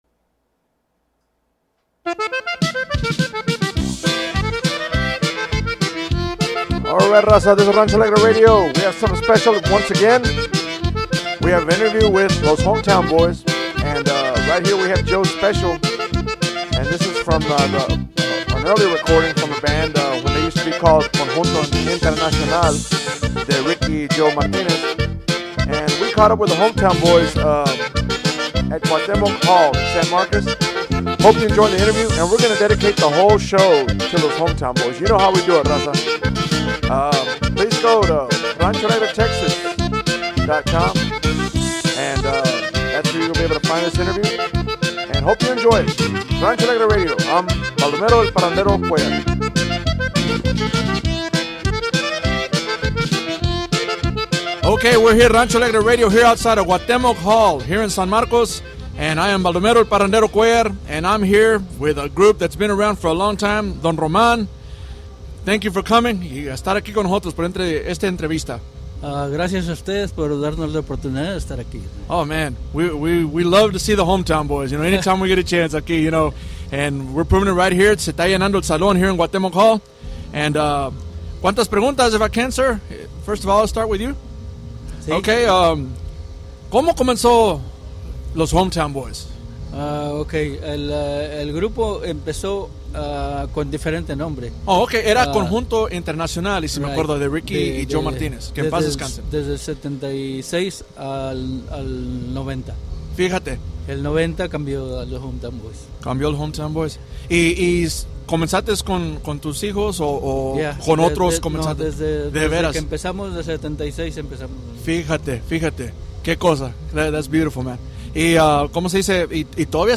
Rancho Alegre Interview - The Hometown Boys